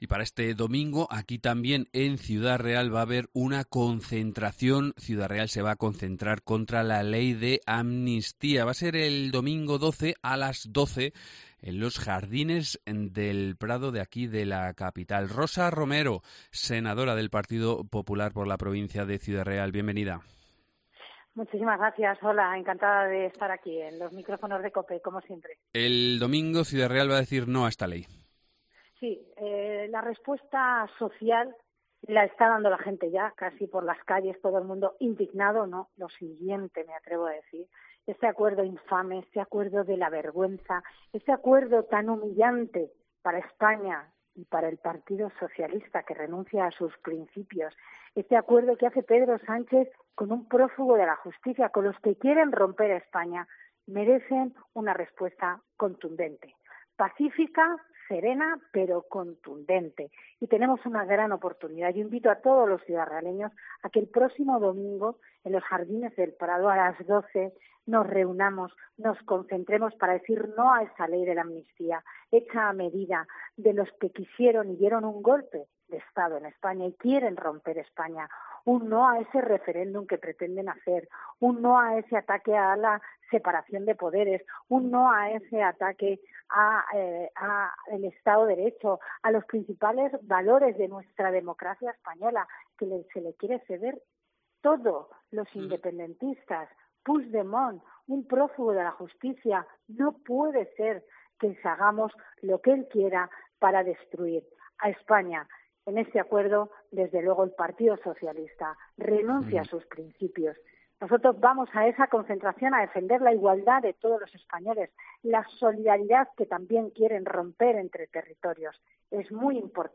Rosa Romero, senadora del PP
Ciudad Real Entrevista Rosa Romero